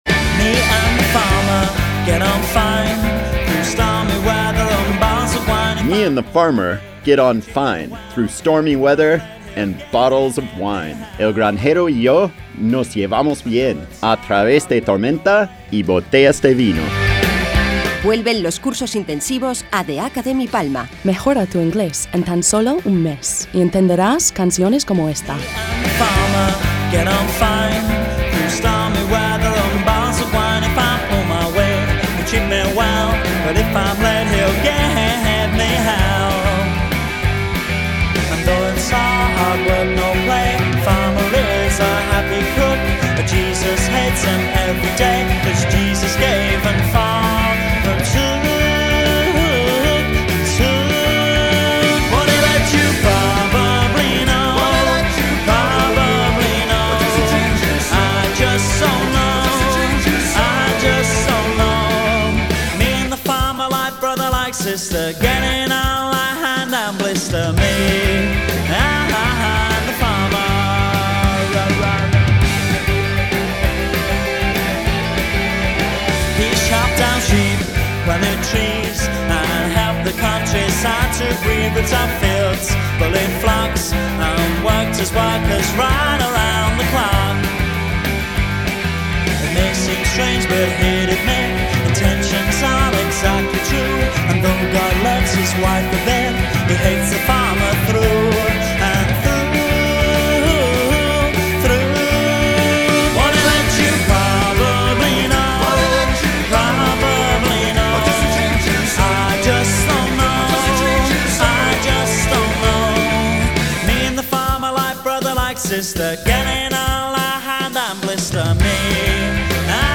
cancion-patrocinada-1-BB1HSjT8.mp3